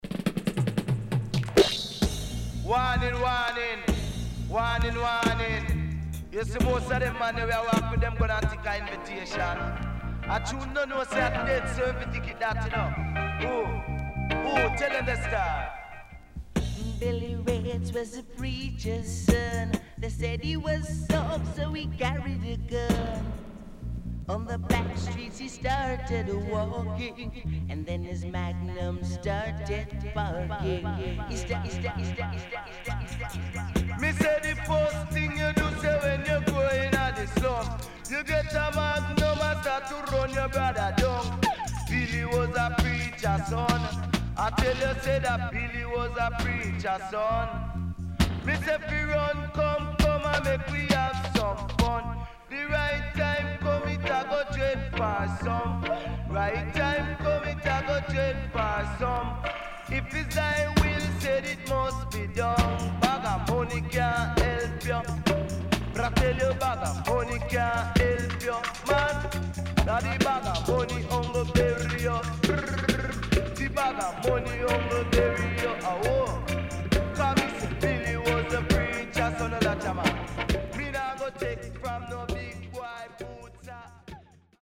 HOME > Back Order [VINTAGE DISCO45]  >  70’s DEEJAY
SIDE A:少しチリノイズ、プチノイズ入ります。